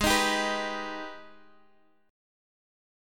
G#mM7 chord